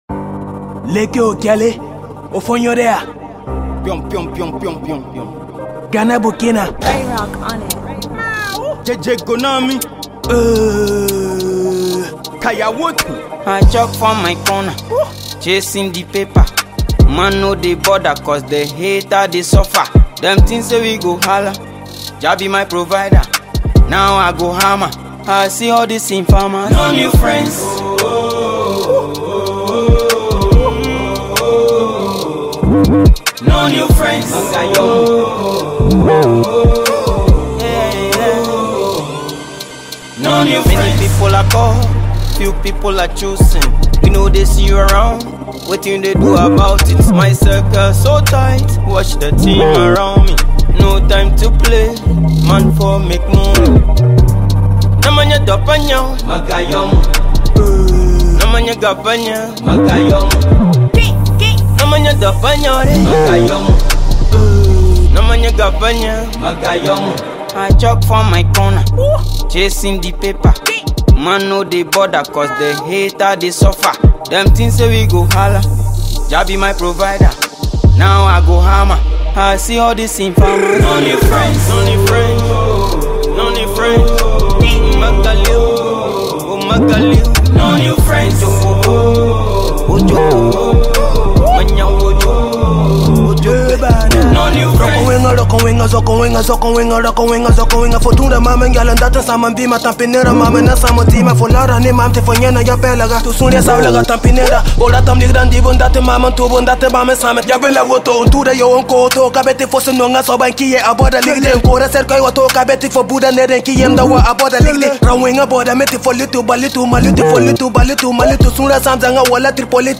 rhythmic percussion, deep basslines, and smooth synths